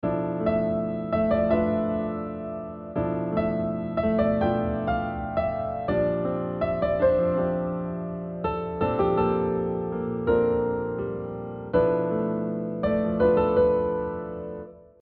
• modal chord successions are not too busy – over the risk of pointing towards the relative major key; and instead help you to create simple contrasts between the modal center and another tonal/modal color
Modal contrast between B Locrian and Bb Lydian